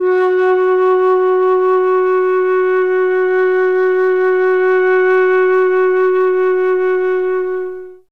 51c-flt03-F#3.wav